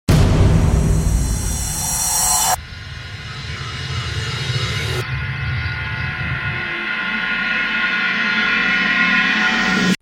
جلوه های صوتی
دانلود صدای تصادف 11 از ساعد نیوز با لینک مستقیم و کیفیت بالا